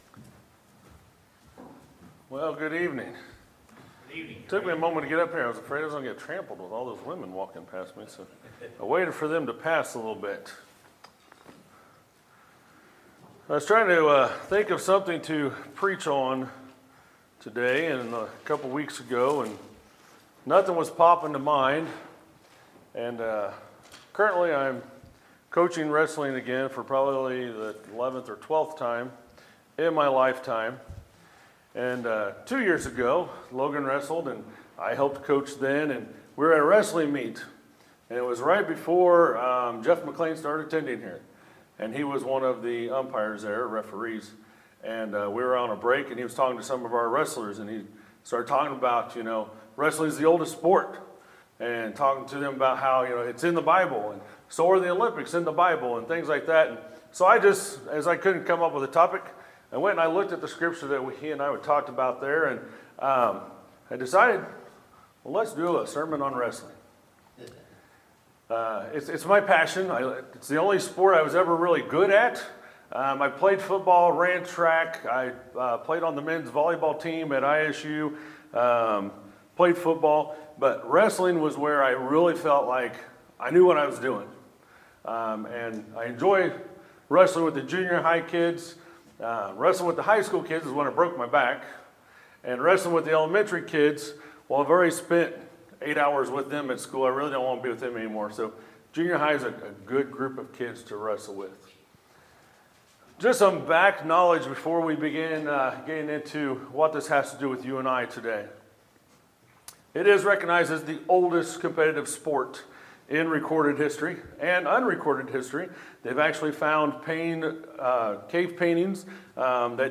Sermons, February 18, 2018